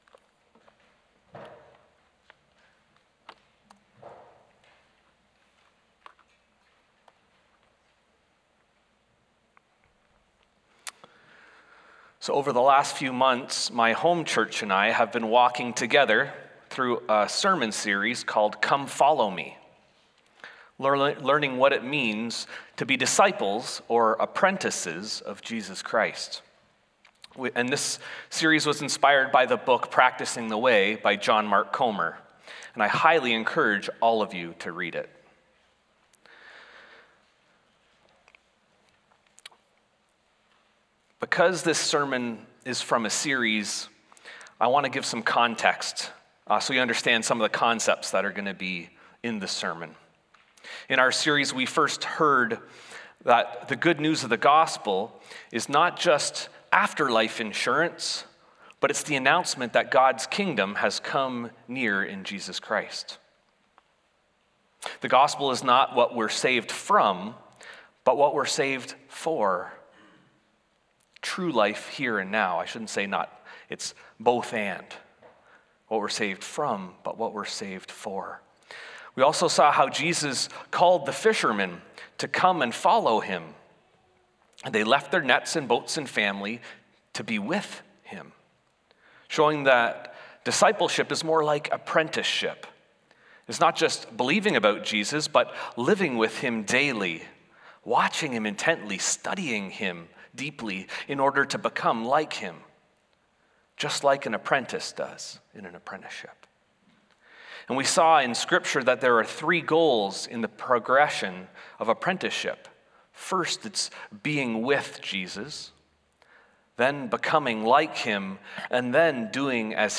Sermons | Community Christian Reformed Church
Guest Speaker